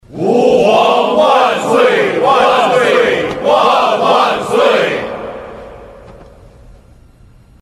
大臣上朝拜见皇帝音效免费音频素材下载